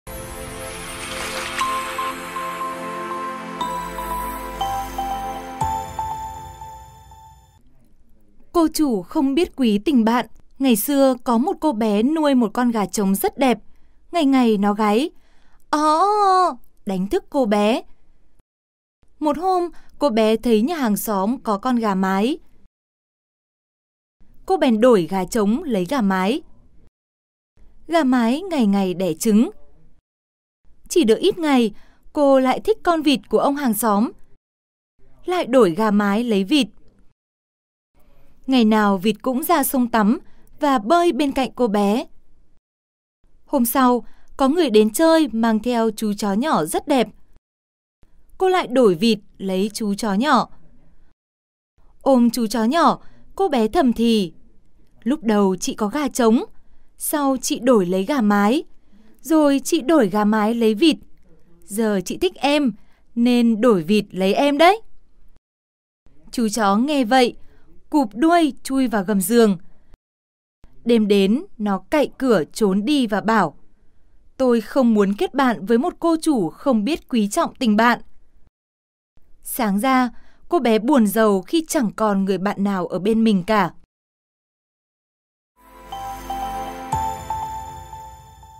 Sách nói | Cô chủ không biết quý tình bạn